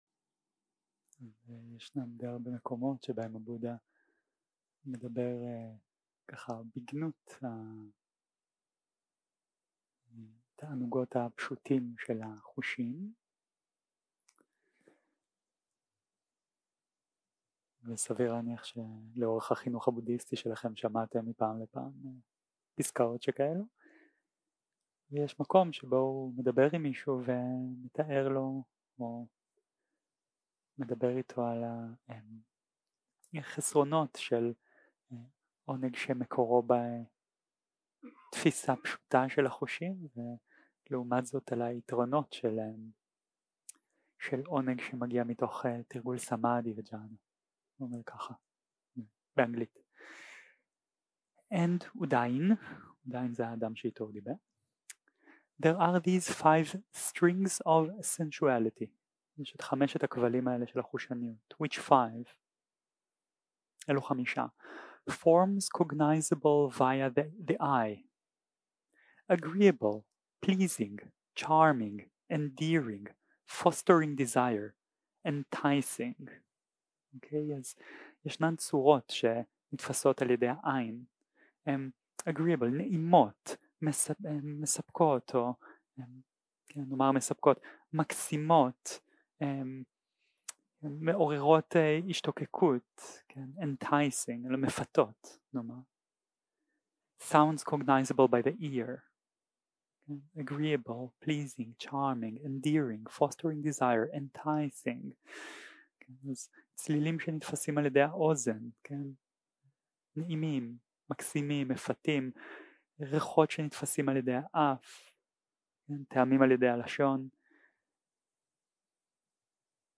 יום 8 - הקלטה 13 - בוקר - הנחיות למדיטציה - תענוגות החושים והעונג של הסמדהי 0:00 0:00 סוג ההקלטה: Dharma type: Guided meditation שפת ההקלטה: Dharma talk language: Hebrew